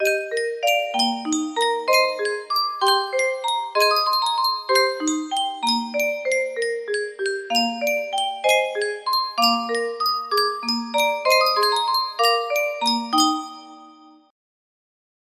Yunsheng Music Box - Anniversary Waltz Y222 music box melody
Full range 60